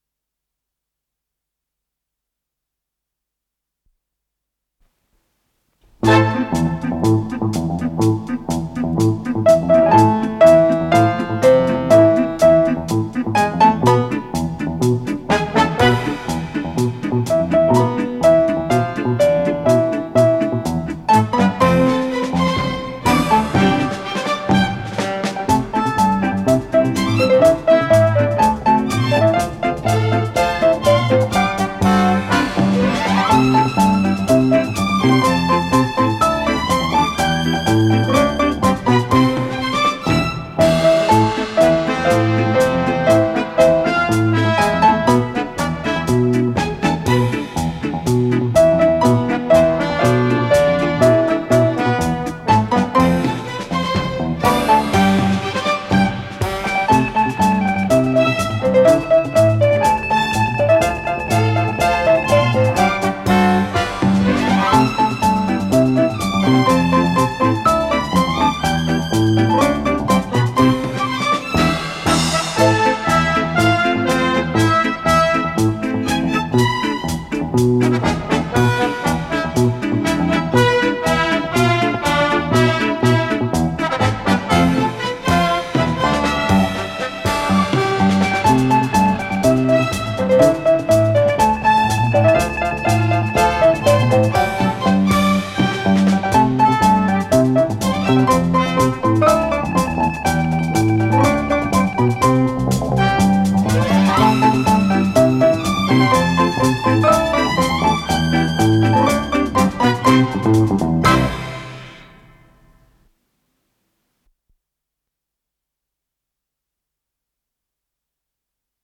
ПодзаголовокИнструментальная заставка, до мажор
Скорость ленты38 см/с
ВариантДубль моно